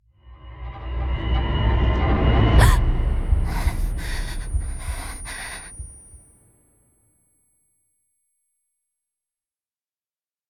sfx_小蝶惊醒喘气_伴随特效.ogg